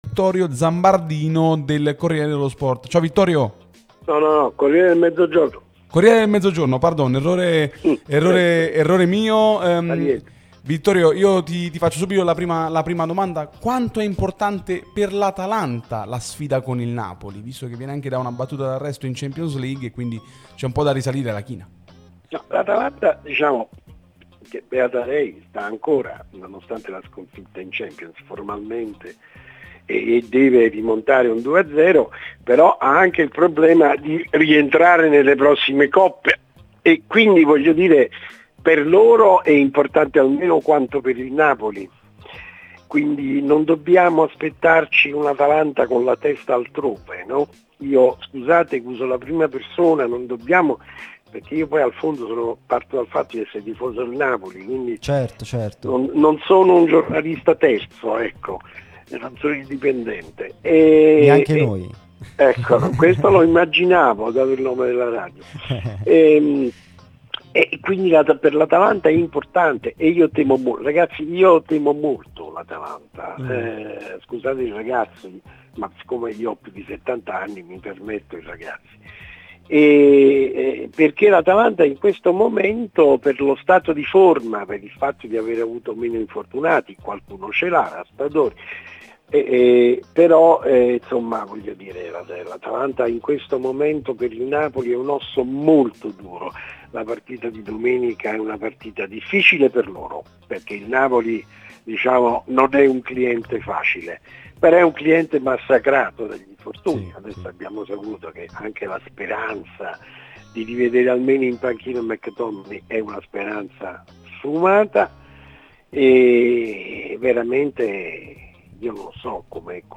Sabato Azzurro' , trasmissione sulla nostra Radio Tutto Napoli